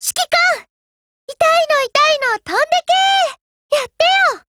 贡献 ） 协议：Copyright，其他分类： 分类:语音 、 分类:少女前线:UMP9 您不可以覆盖此文件。